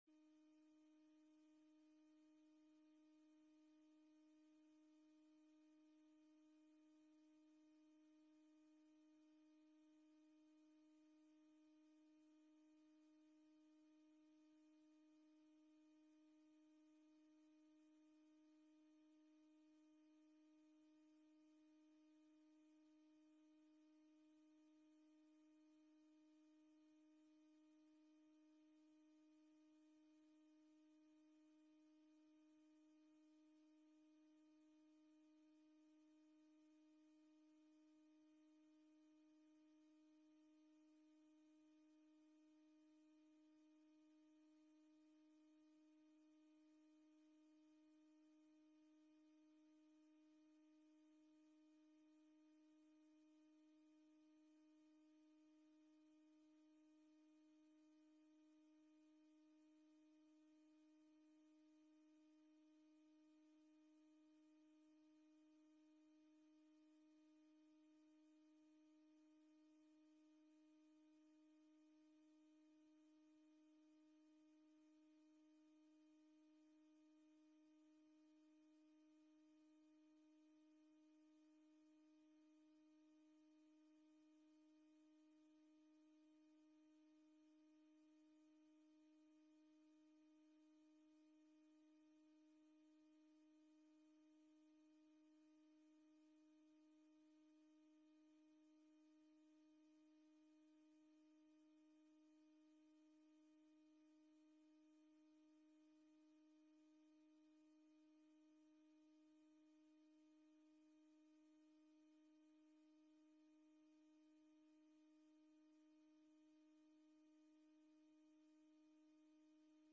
De vergadering wordt gehouden in de Raadzaal met in achtneming van de 1,5 meter regel.